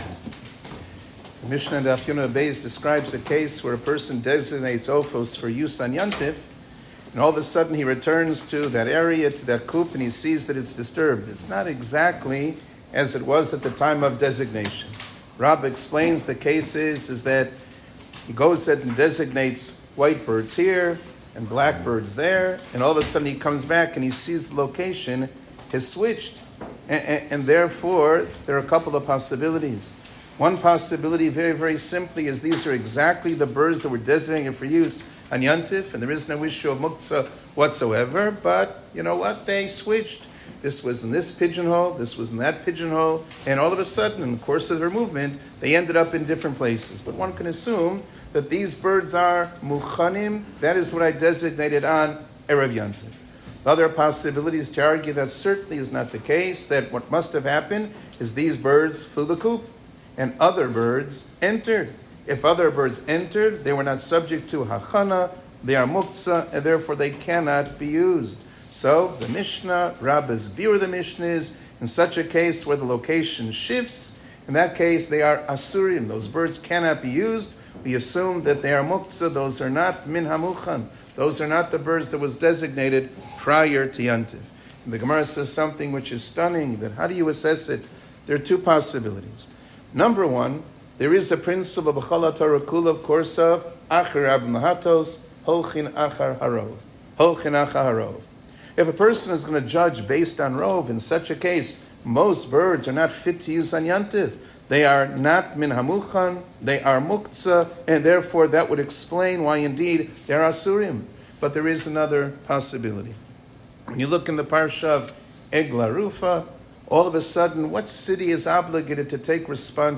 שיעור כללי - רוב וקרוב